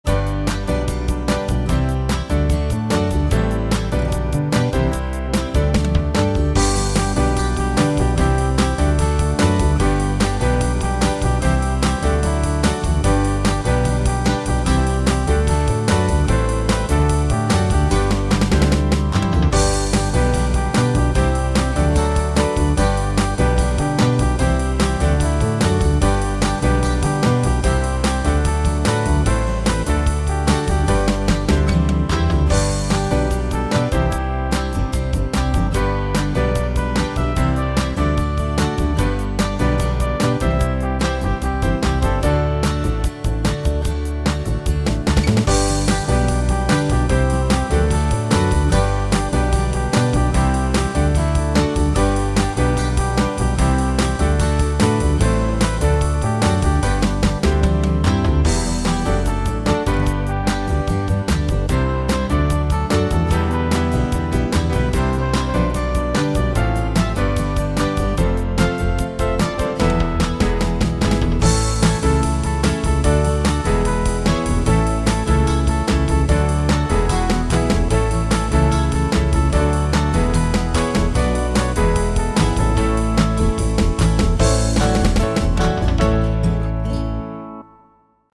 A usable liturgical song.